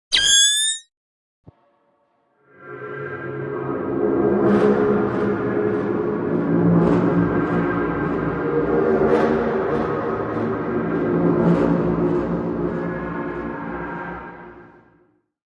Horror
Horrors of Nightvision is a free horror sound effect available for download in MP3 format.